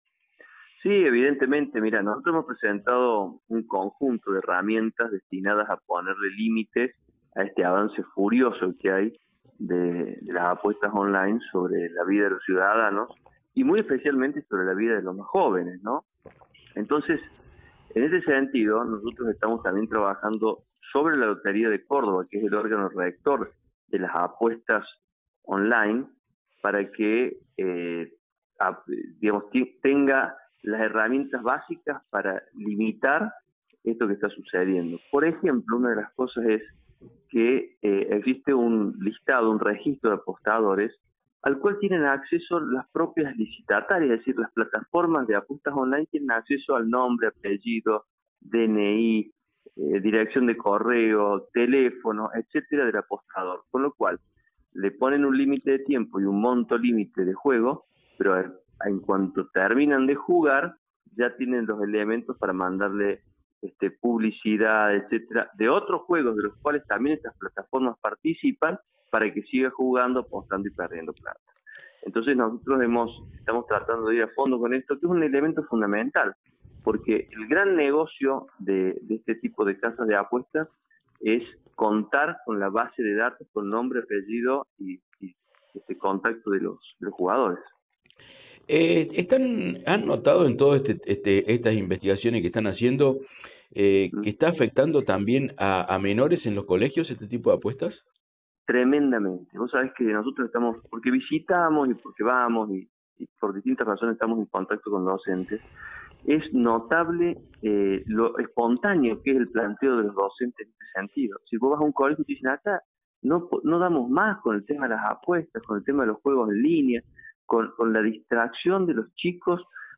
Audio: Rodrigo Agrelo (Legislador Encuentro Vecinal Córdoba).